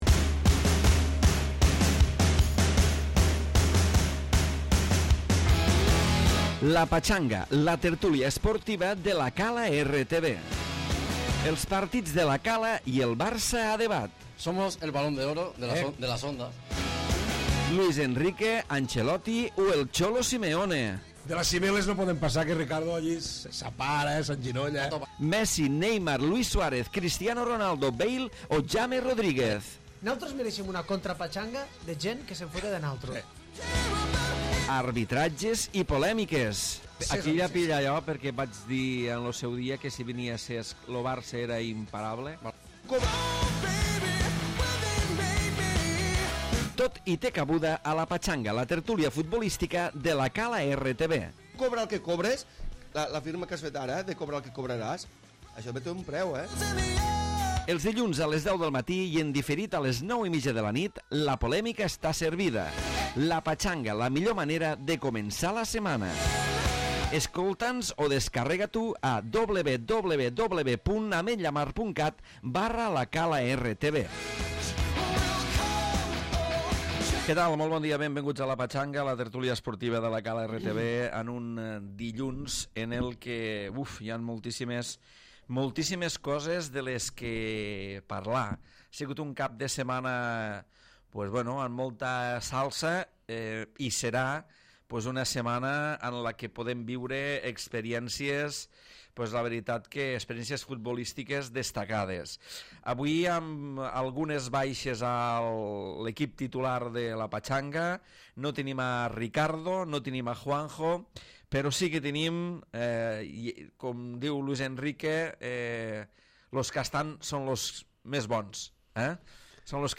Tertúlia esportiva que analitza l'actualitat de la primera línia de futbol, del Barça i del Reial Madrid.